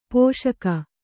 ♪ pōṣaka